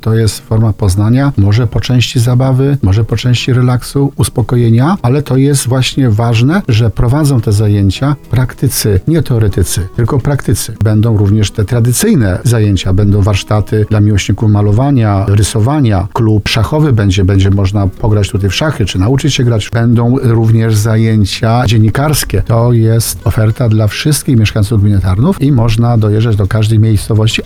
– mówił wójt gminy Tarnów Grzegorz Kozioł